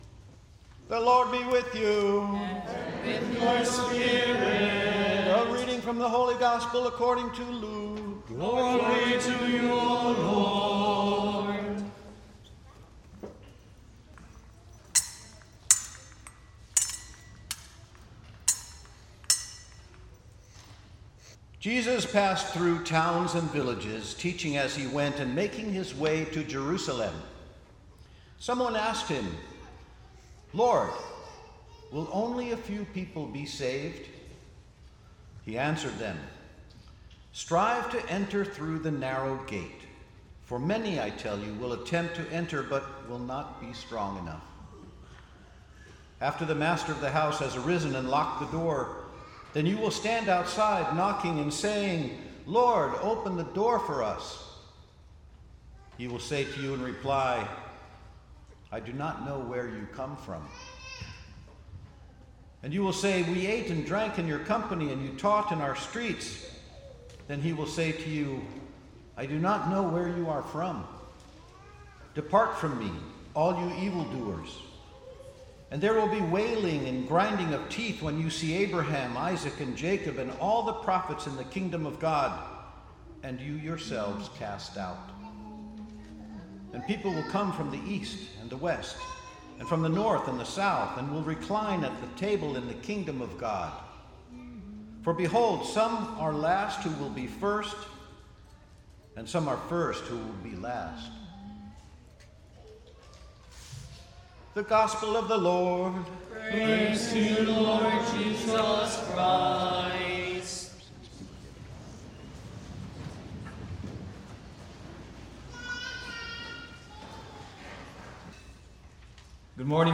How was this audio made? ***At the end, the last five minutes is a bonus hymn from our 10AM Holy Mass***